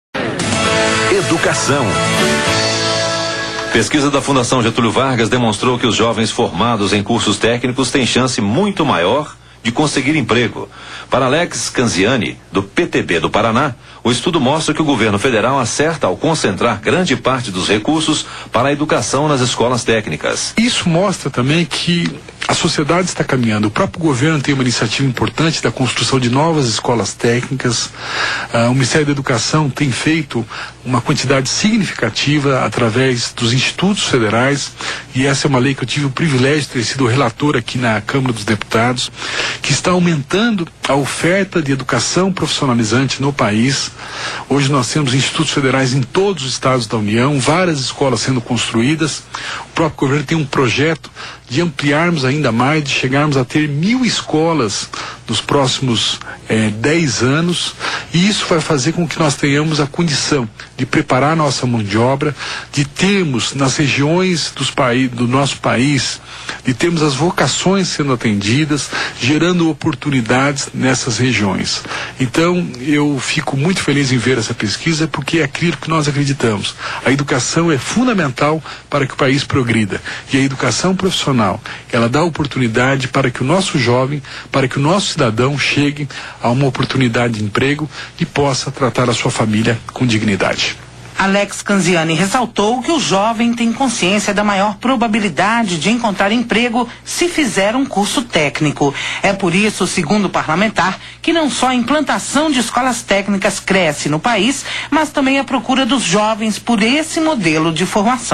Setembro/2010 Jovens formados em cursos técnicos tem mais chances de conseguir emprego, segundo FGV Meio: Rádio Band News - SP Mídia: Rádio Temas / Subtemas Desenv.